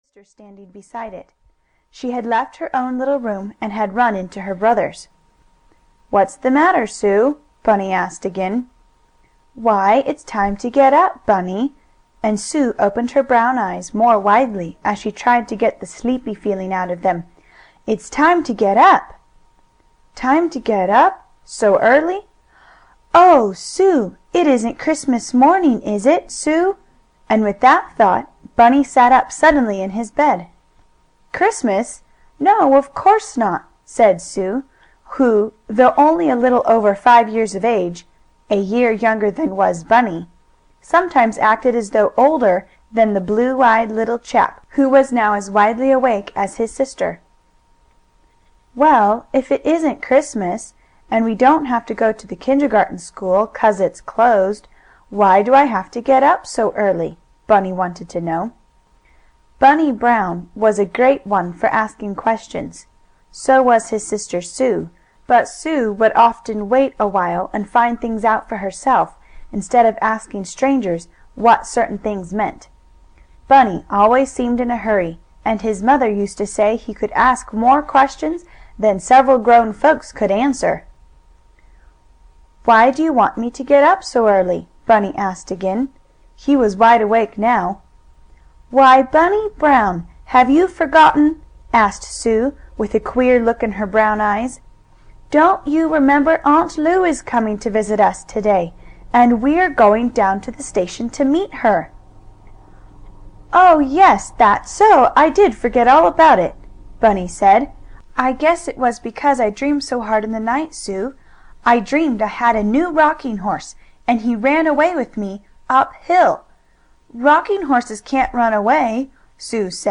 Bunny Brown and his Sister Sue (EN) audiokniha
Ukázka z knihy